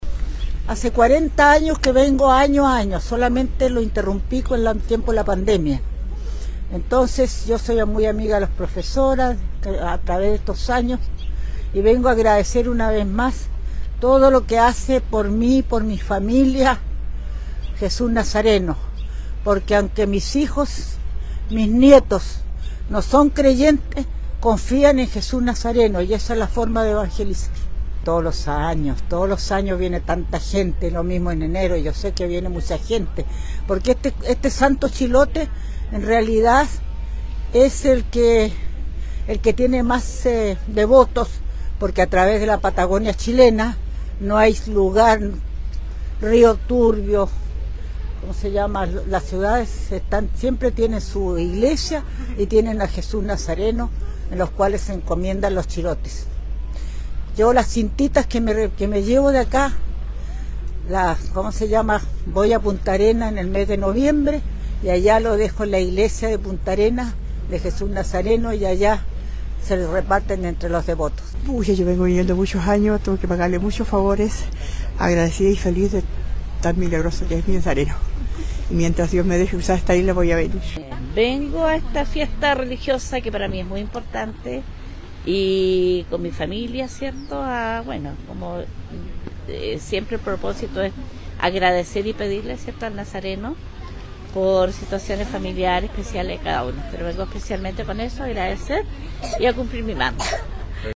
01-FIELES-CAGUACH.mp3